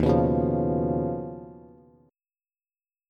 D#7sus2#5 Chord
Listen to D#7sus2#5 strummed